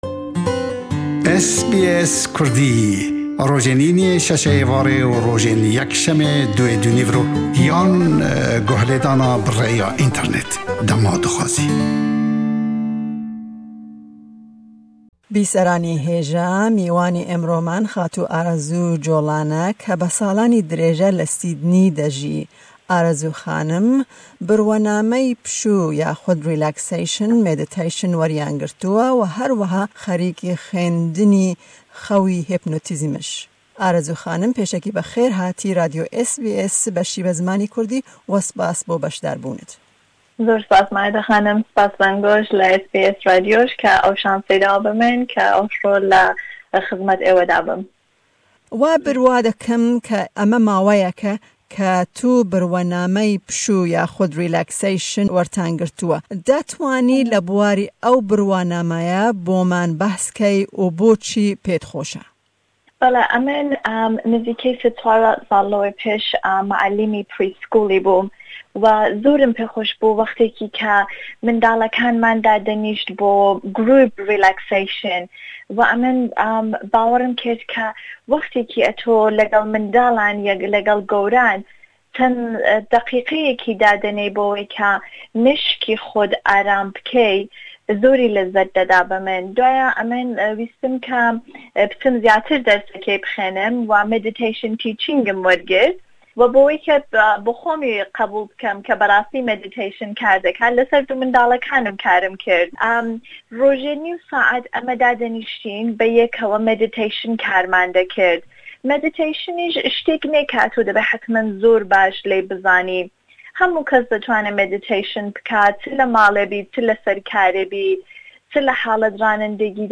Ew di vê hevpeyvînê de ji me re girîngiya aramkirina ruhî shîrove dike, ku herweha bo zarokan jî gelekî bashe.